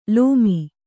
♪ lōmi